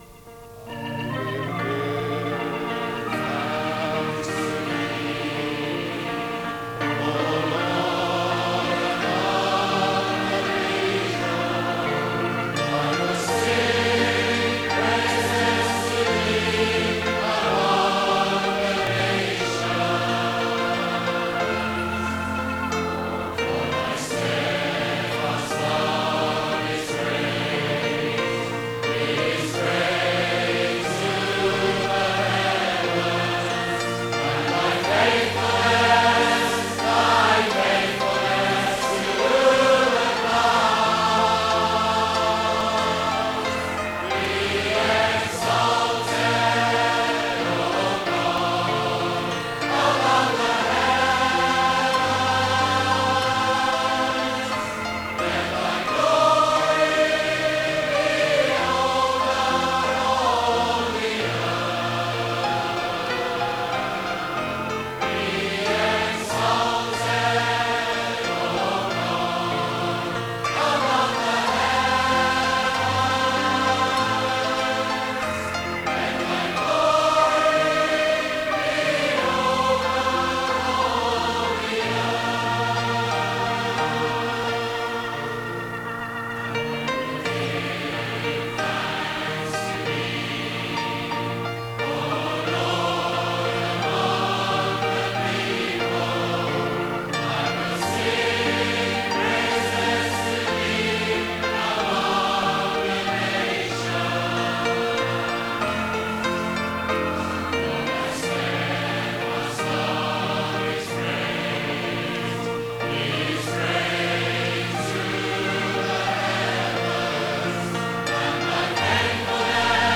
Others' stuff, Worship, Music
piano
Hammond organ with twin Leslie speakers
And a great sound too.